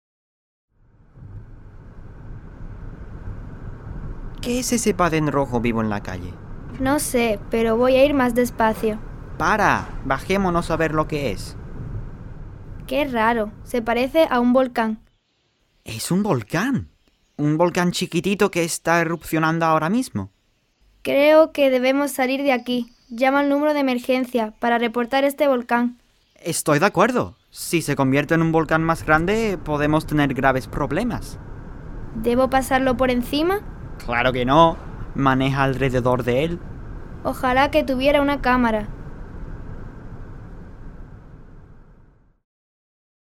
Whimsical Dialogues for Upper Level Classes
The Spanish enactment (mp3) can be played while the students read along.